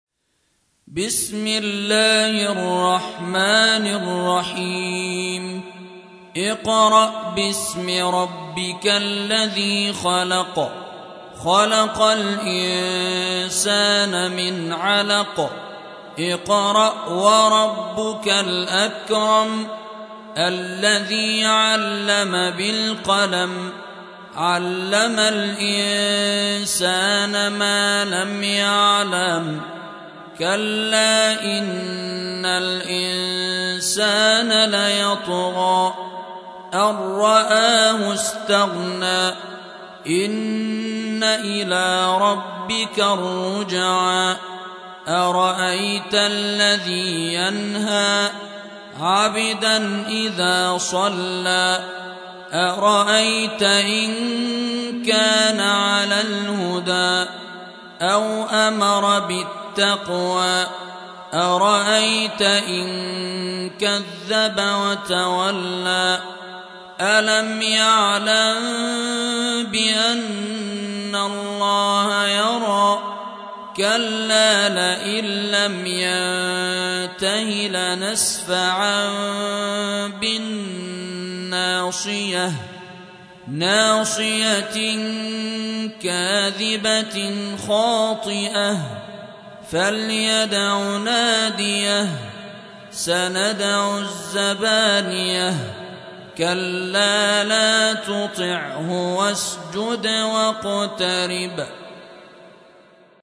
96. سورة العلق / القارئ